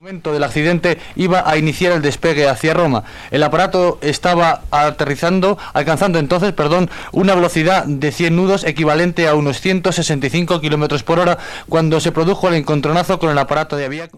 Informació des de les proximitats de les restes d'un dels avions.
Informatiu